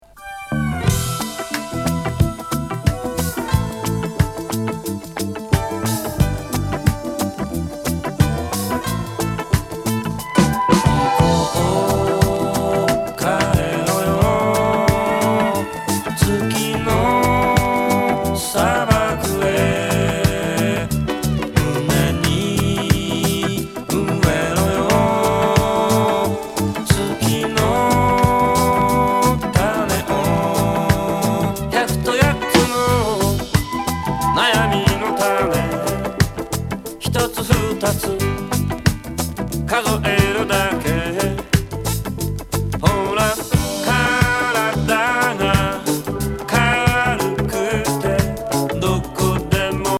電子エスノ・グルーヴィー